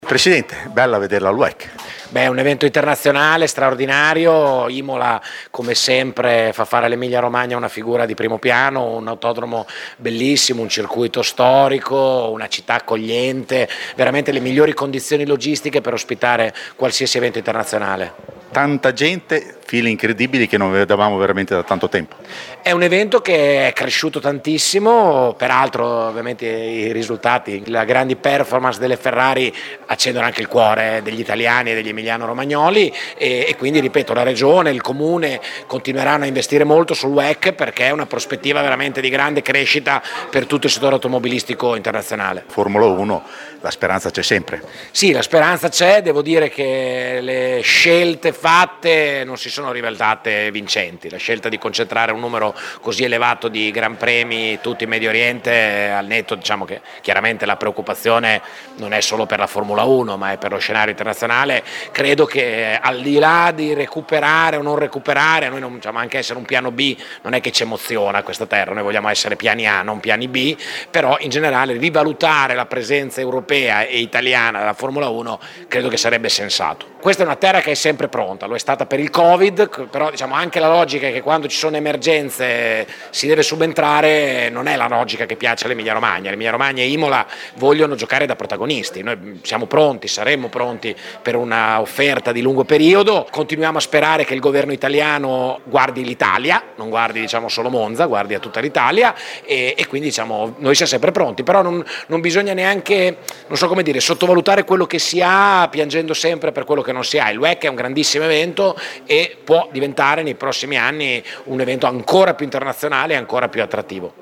Ascolta Michele De Pascale, Presidente della Regione Emilia-Romagna